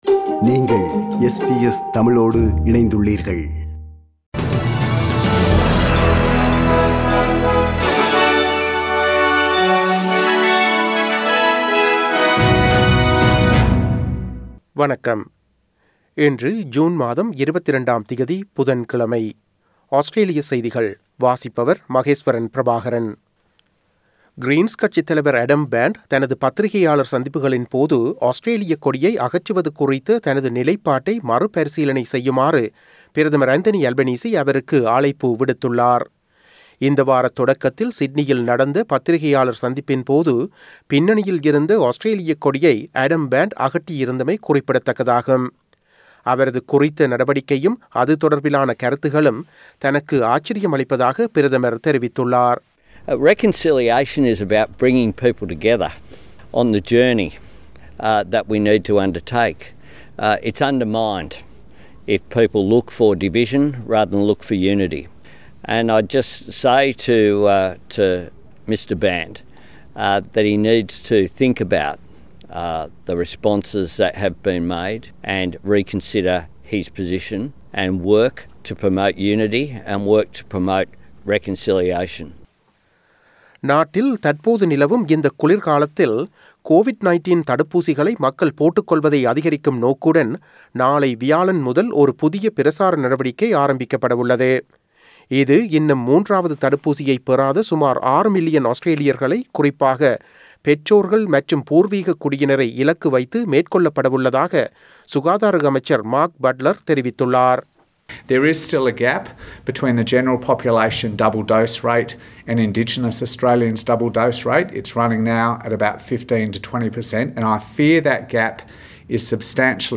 Australian news bulletin for Wednesday 22 June 2022.